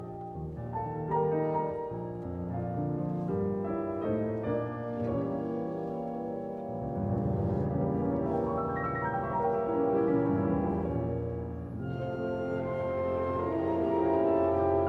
A la mes. 100, env. 5'01'', le Thème 1 va être varié au piano avec, toujours, son jeu d'arpèges et d'octaves.